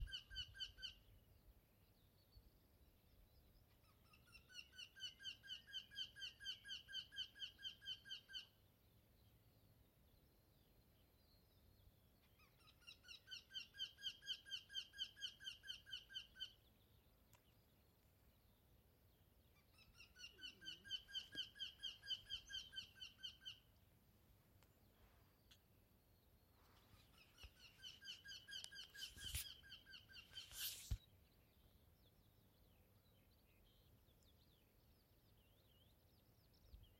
Putni -> Dzeņi ->
Tītiņš, Jynx torquilla
StatussDzied ligzdošanai piemērotā biotopā (D)